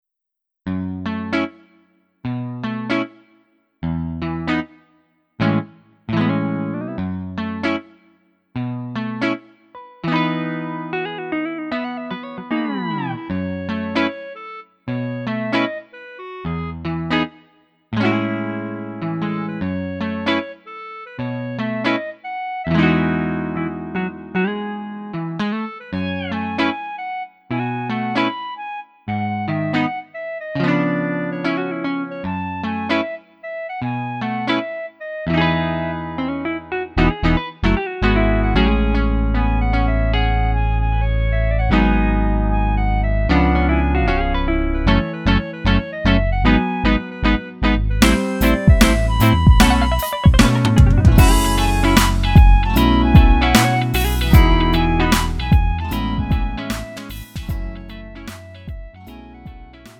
음정 원키 3:53
장르 구분 Lite MR